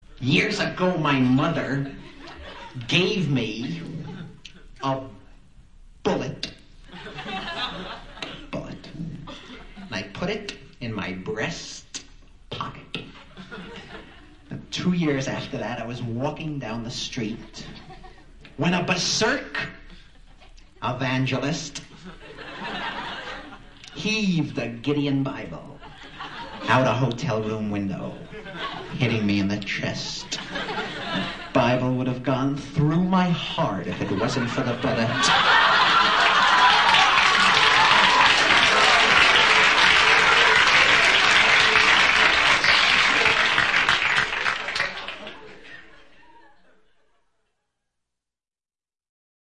几乎5秒一个富有哲思的包袱带你体会美国单口相声的不同之处。